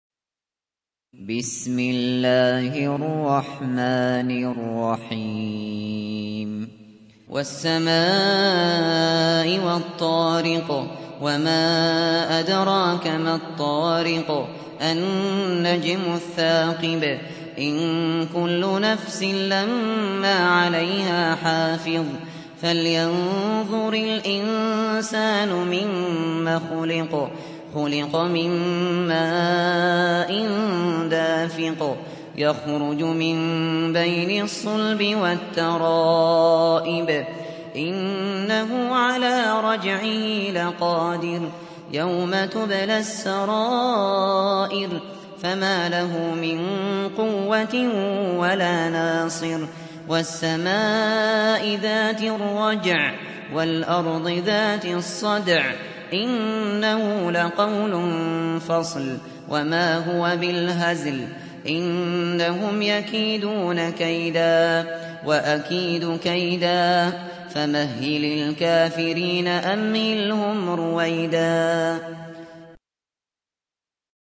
Récitation par Abu Bakr Al Shatri